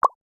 Click_Button.wav